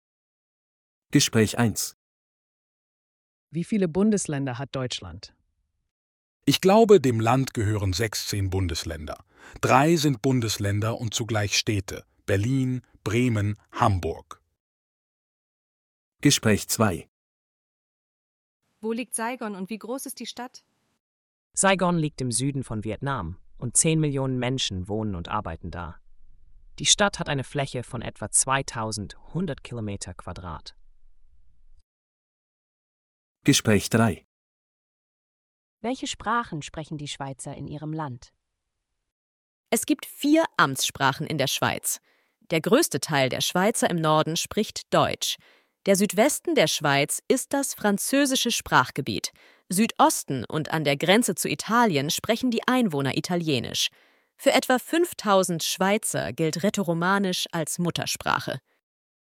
Audio text for the dialogs in task 4: